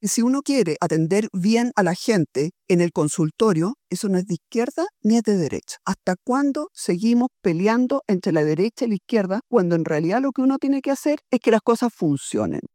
En conversación con Radio Bío Bío de Temuco, la abanderada de Chile Vamos, aseguró que más allá de los debates de “izquierda o derecha”, lo que importa es que las cosas funcionen.